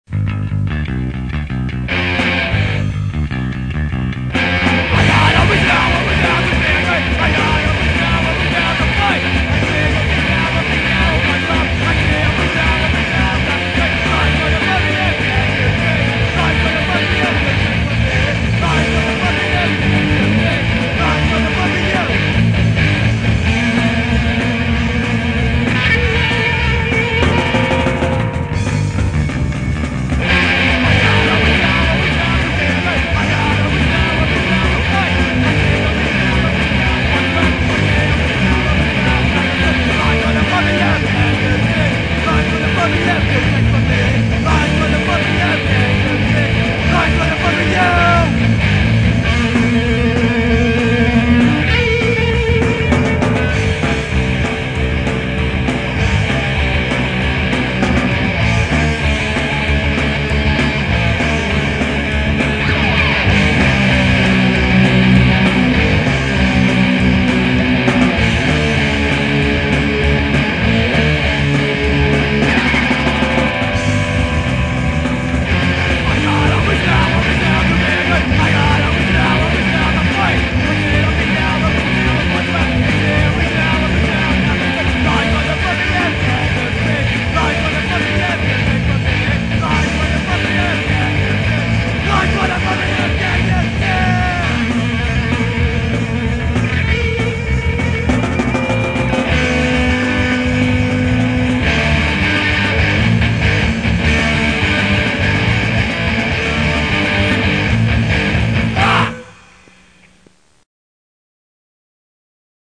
punk rock See all items with this value
pop punk See all items with this value